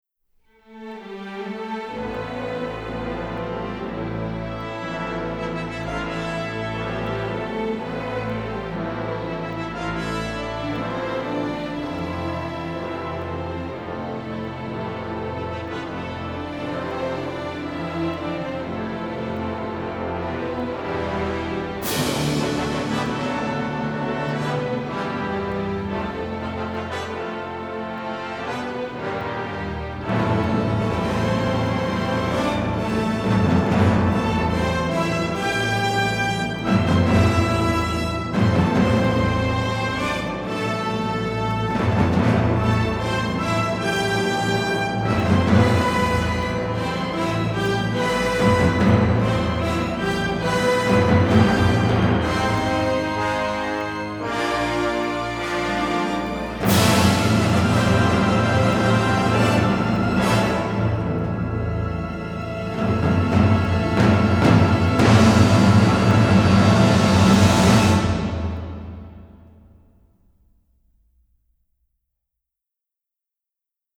records and mixes at AIR Studios in London